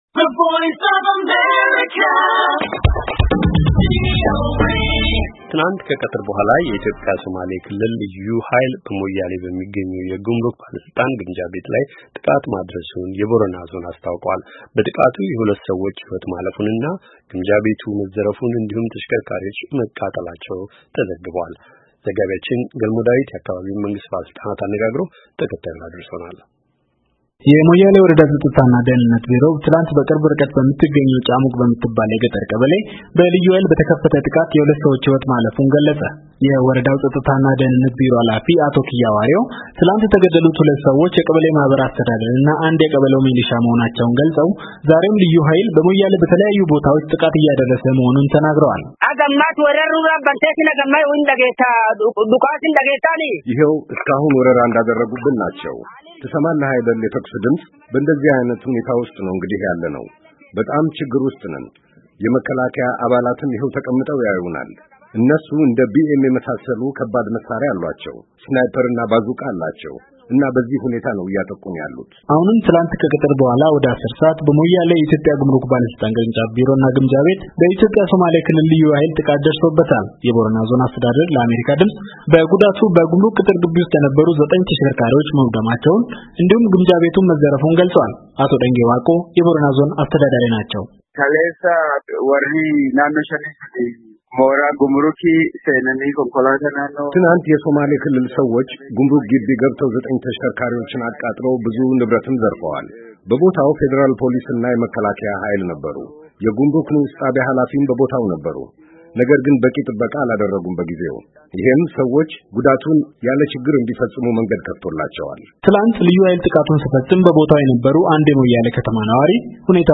የአካባቢውን መንግሥት ባለሥልጣናት አነጋግሮ ተከታዩን ዘገባ ልኮልናል::